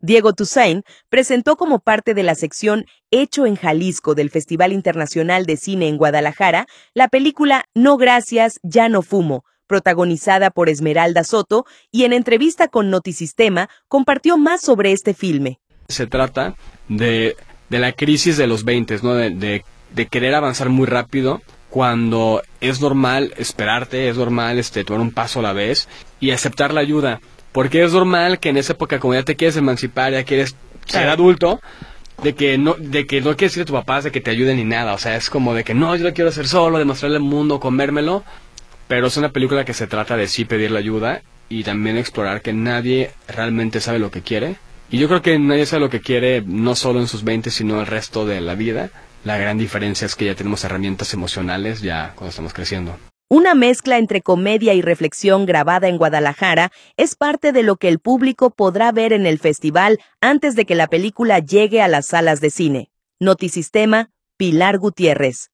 y en entrevista con Notisistema compartió más sobre este filme.